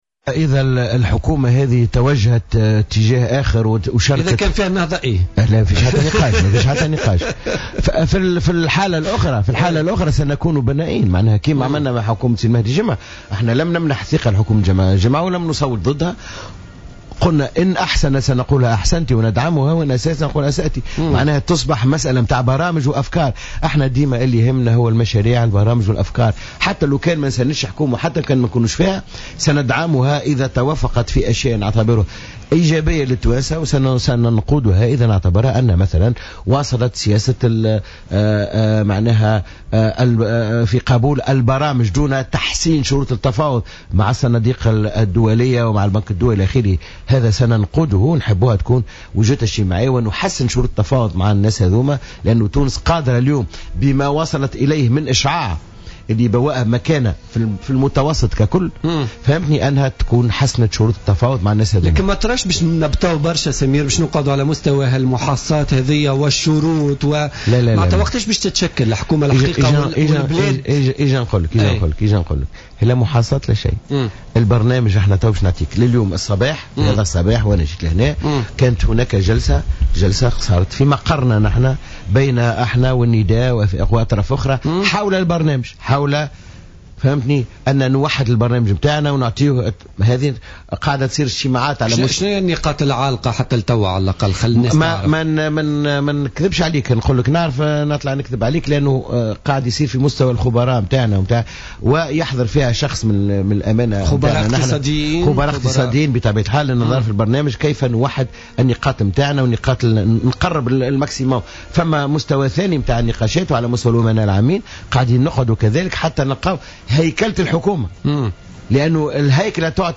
قال الأمين العام لحزب المسار الديمقراطي،سمير بالطيب ضيف برنامج "بوليتيكا" اليوم الاثنين إن المسار عقد صباح اليوم جلسة بمقرّه بحضور ممثلي حزبي النداء وآفاق تونس للتشاور حول هيكلة الحكومة القادمة.